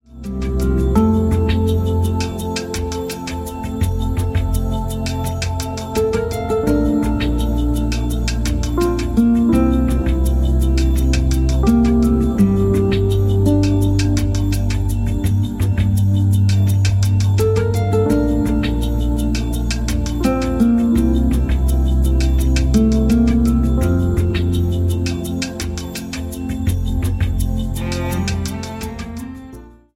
• Afslappende mild R&B musik
• Velegnet til wellness afslapning og baggrundsmusik